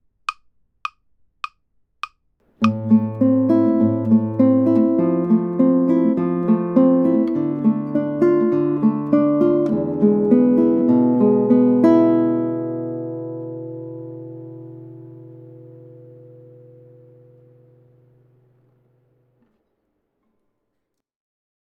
This pattern is played twice in a measure of 4/4 meter, creating an eighth note flow.
P-i-m-a 2X Arpeggio | Chords with P-i-m-a 2X from Kojo no Tsuki's first line.